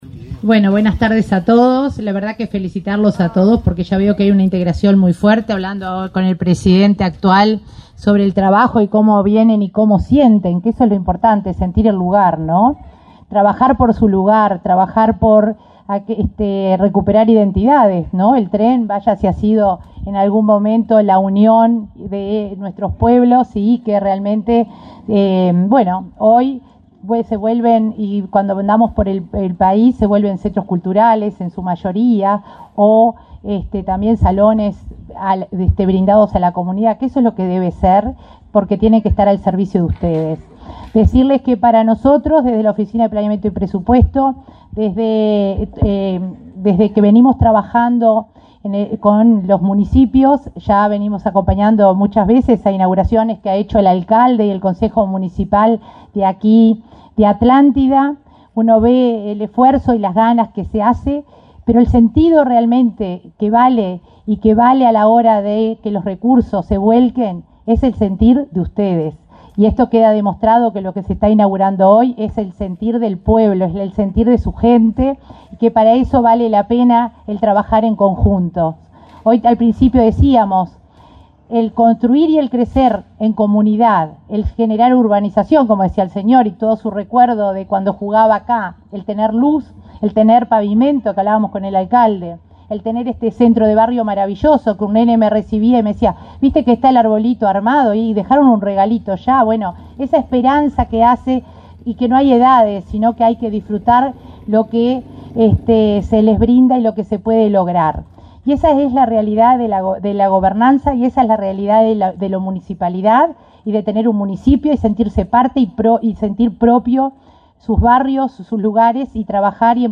Palabras de la coordinadora de Descentralización de OPP, María de Lima
La coordinadora de la OPP realizó declaraciones.